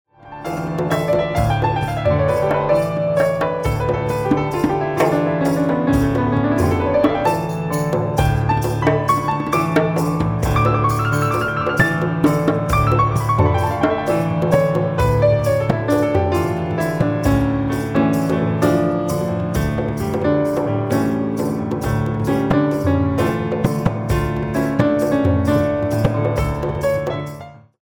Piano & Percussion